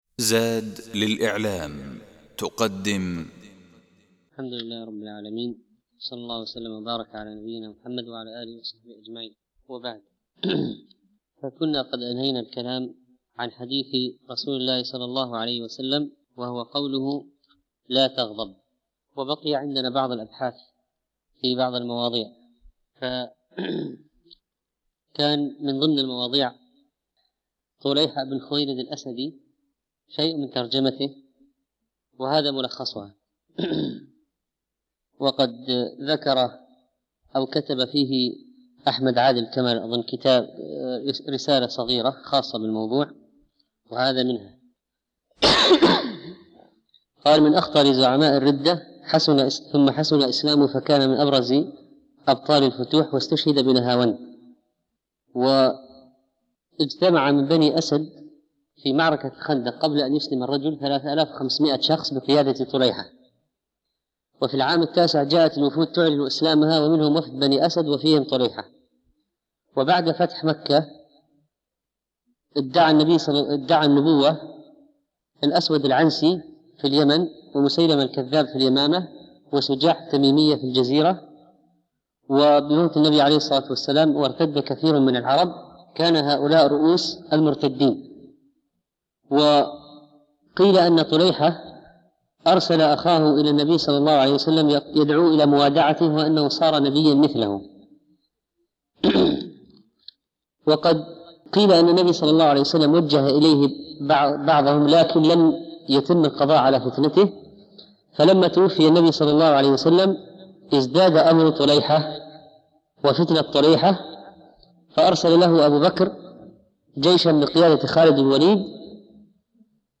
(18) الدرس الثامن عشر